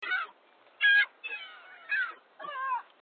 seagull1.mp3